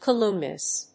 音節ca・lum・ni・ous 発音記号・読み方
/kəlˈʌmniəs(米国英語)/